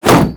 shield_hitted.wav